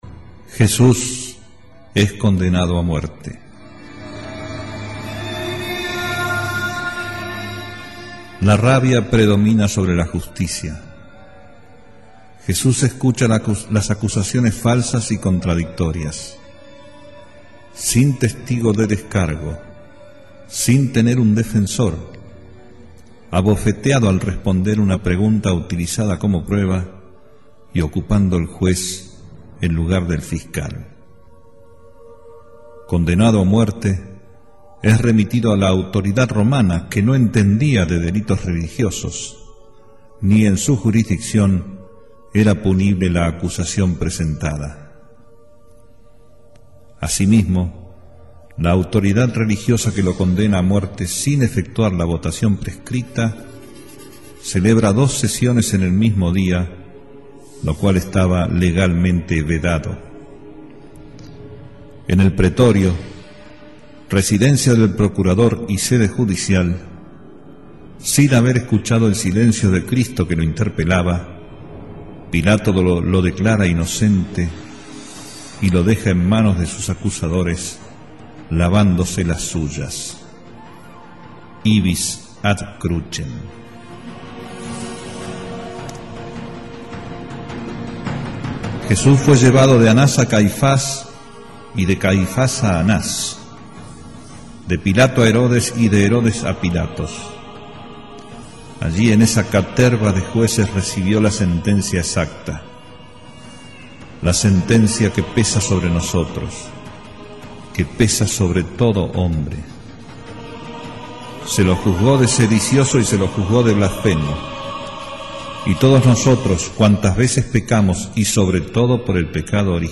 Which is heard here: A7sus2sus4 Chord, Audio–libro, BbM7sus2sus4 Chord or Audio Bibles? Audio–libro